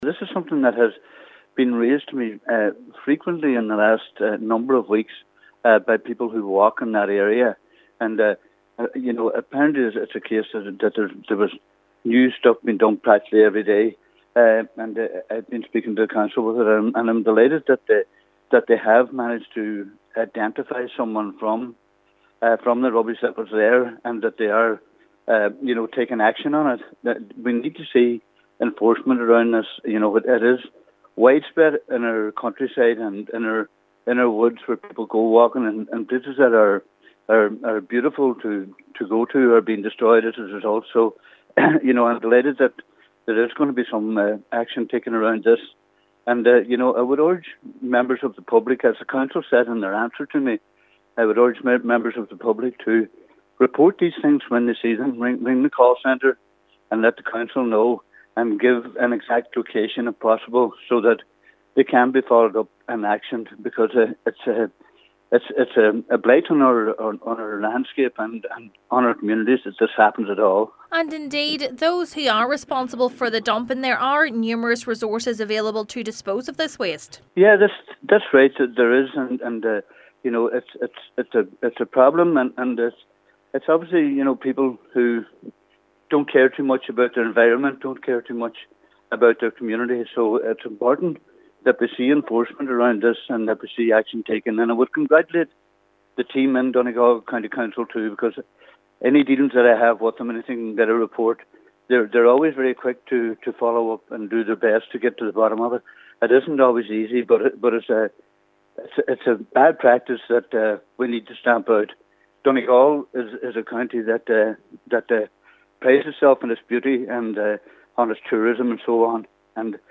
He says dumping is a blight on Donegal’s landscape: